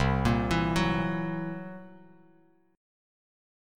C7b5 chord